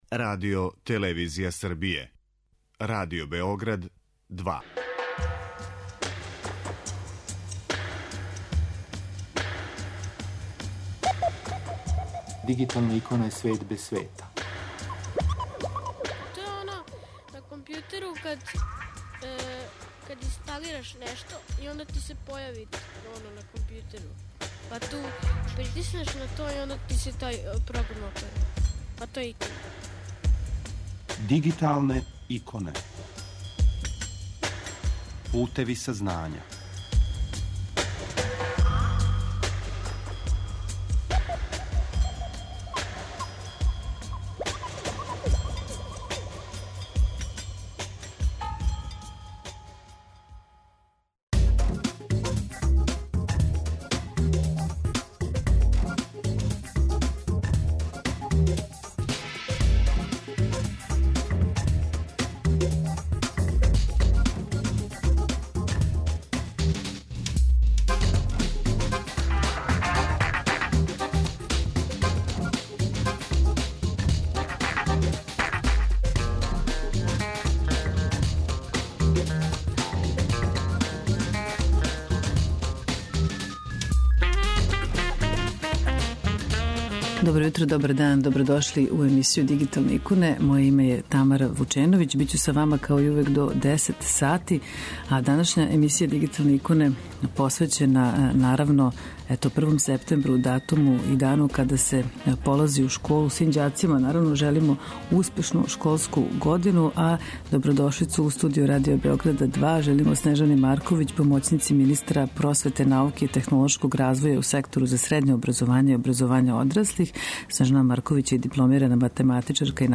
Са нама уживо на таласима Радио Београда 2 је Снежана Марковић, помоћница министра просвете, науке и технолошког развоја у Сектору за средње образовање и образовање одраслих, дипломирана математичарка и наставница рачунарства и информатике.